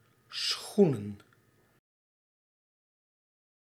Ääntäminen
Ääntäminen Tuntematon aksentti: IPA: /ˈsxu.nə(n)/ Haettu sana löytyi näillä lähdekielillä: hollanti Käännöksiä ei löytynyt valitulle kohdekielelle.